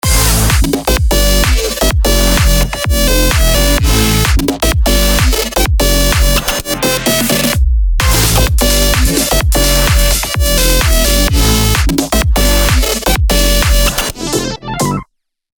מנסה סגנון חדש וקשוח